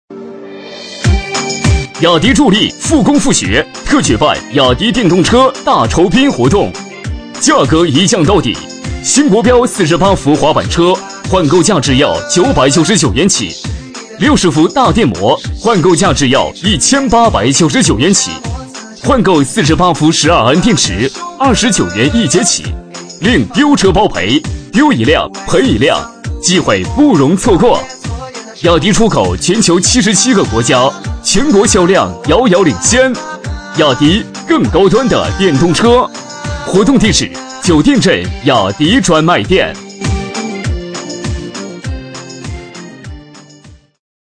B类男10
【男10号促销】雅迪
【男10号促销】雅迪.mp3